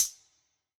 Index of /musicradar/ultimate-hihat-samples/Hits/ElectroHat C
UHH_ElectroHatC_Hit-25.wav